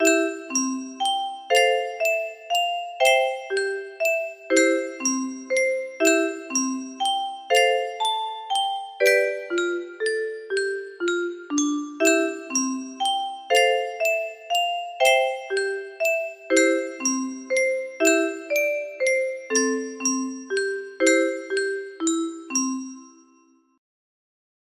BPM 60